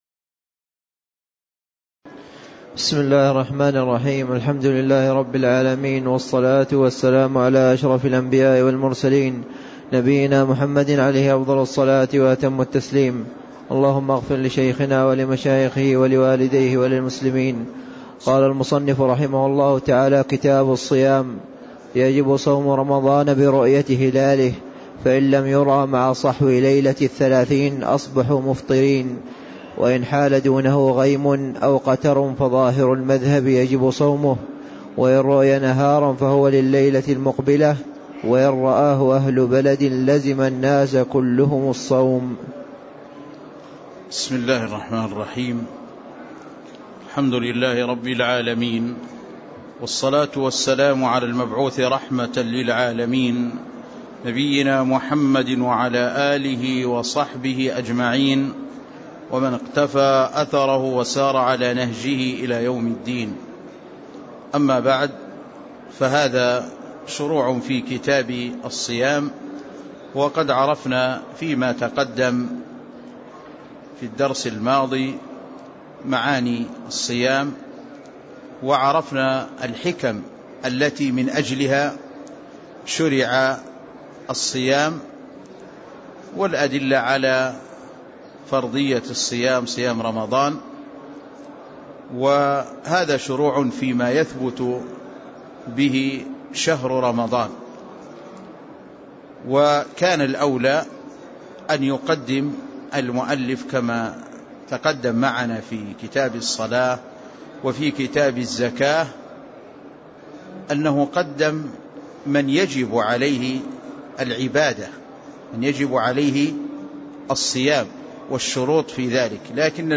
تاريخ النشر ٣ رمضان ١٤٣٦ هـ المكان: المسجد النبوي الشيخ